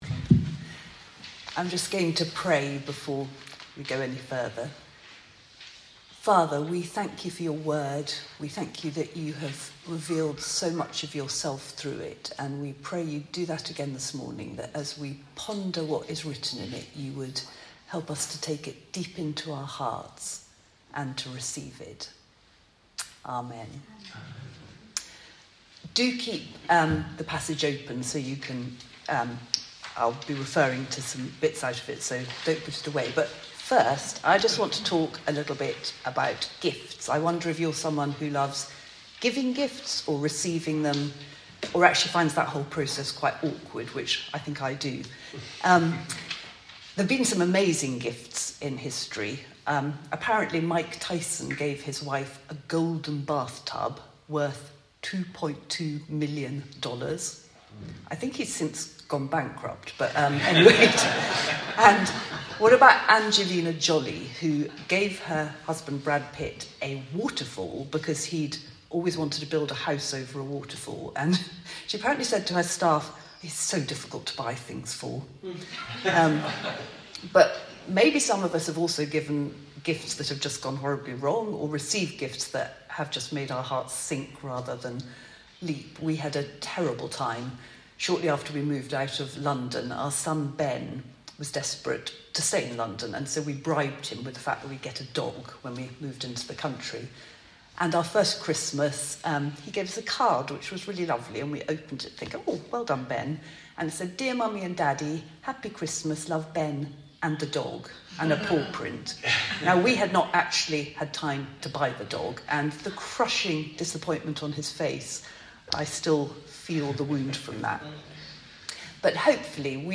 On a glorious morning, with the sun streaming through the windows of St Swithun’s, a good congregation gathered for Valley Worship on Sunday in Martyr Worthy.